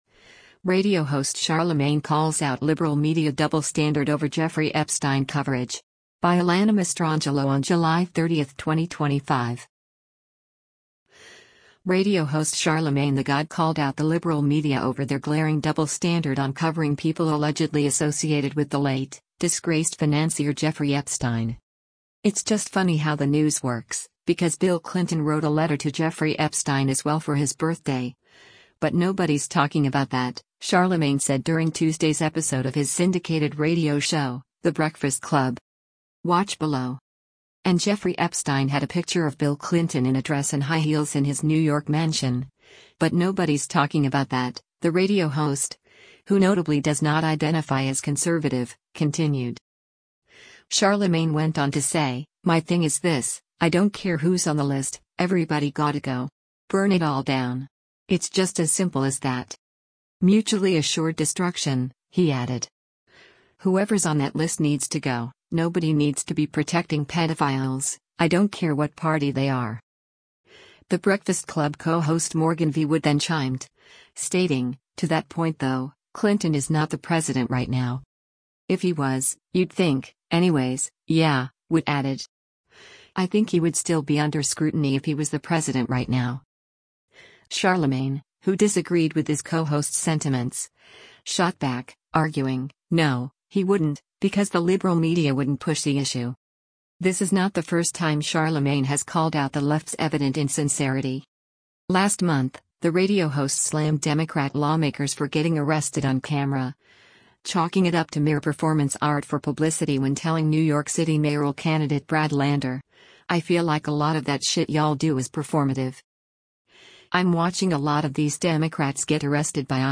Radio host Charlamagne tha God called out the “liberal media” over their glaring double standard on covering people allegedly associated with the late, disgraced financier Jeffrey Epstein.
“It’s just funny how the news works, because Bill Clinton wrote a letter to Jeffrey Epstein as well for his birthday, but nobody’s talking about that,” Charlamagne said during Tuesday’s episode of his syndicated radio show, The Breakfast Club.